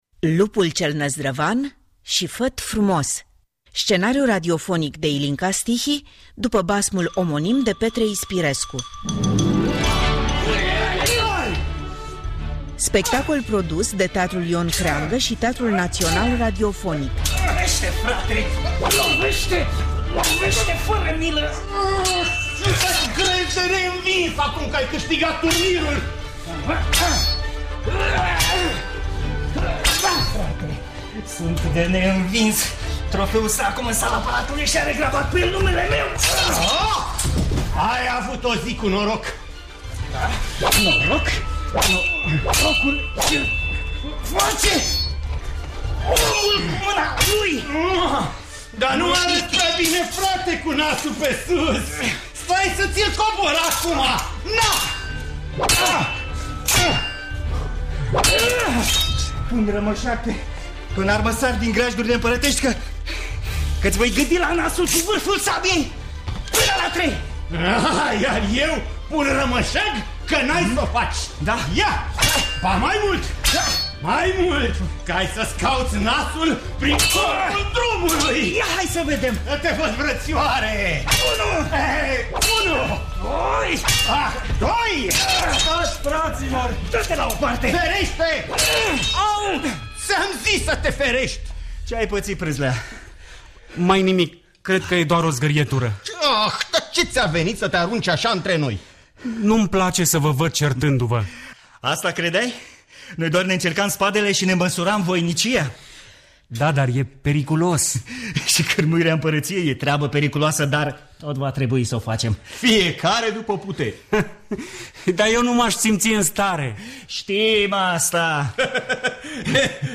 Există însă un basm mai puțin cunoscut al acestui mare povestitor, și a fost dramatizat în 2017, în cadrul unui admirabil proiect al Teatrului Național Radiofonic pentru copii.